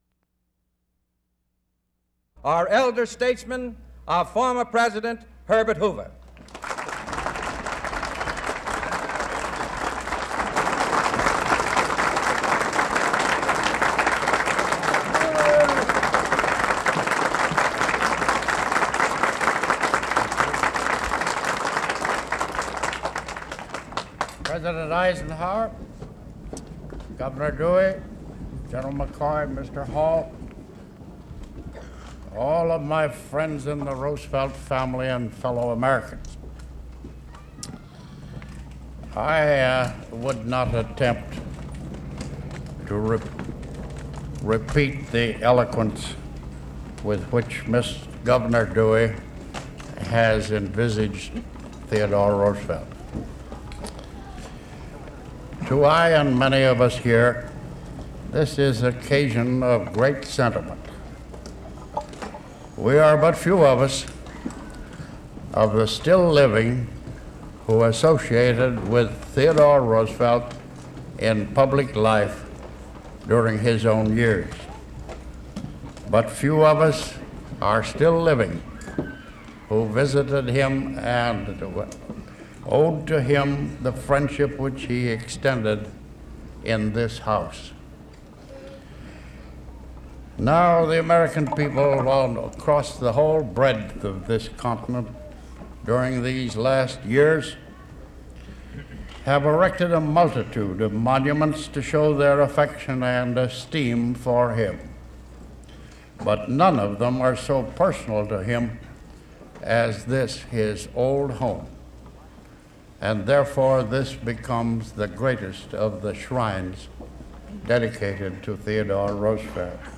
Former U.S. President Herbert Hoover delivers a speech at the dedication of Sagamore Hill, former president Theodore Roosevelt's home, as a national park